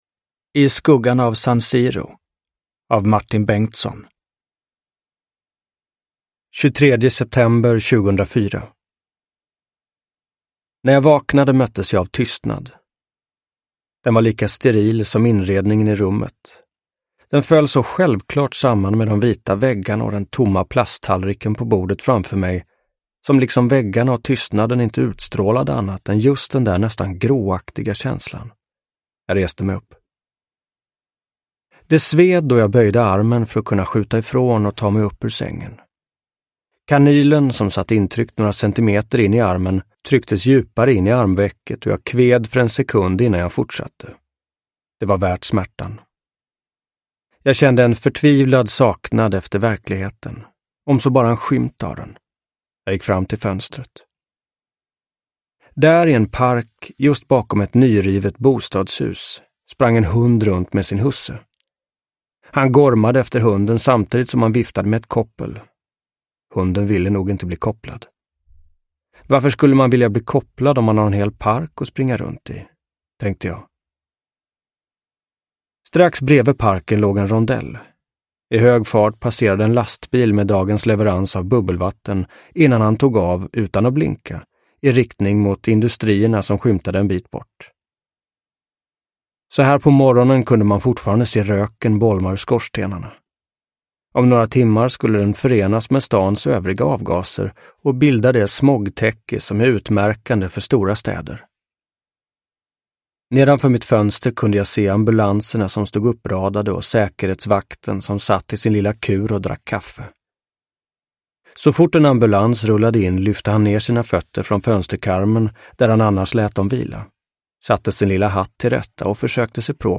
I skuggan av San Siro : från proffsdröm till mardröm – Ljudbok – Laddas ner
Uppläsare: Martin Wallström